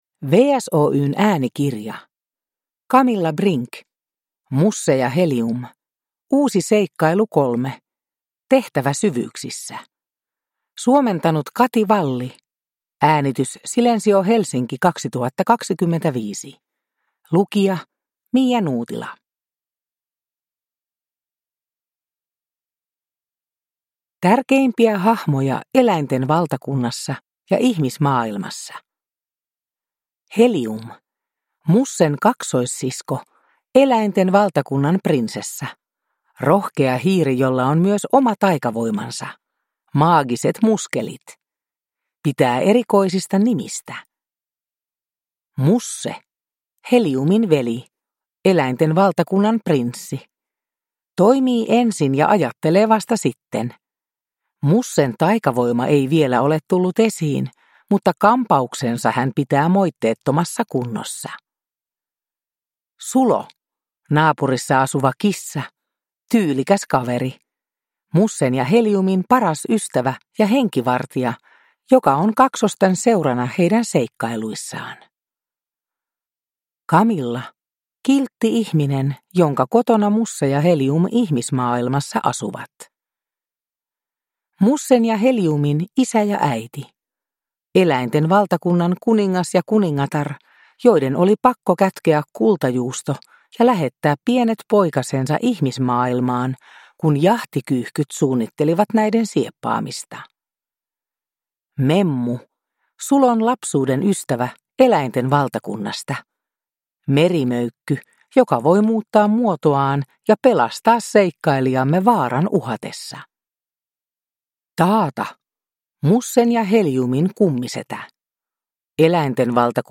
Musse ja Helium Uusi seikkailu 3: Tehtävä syvyyksissä – Ljudbok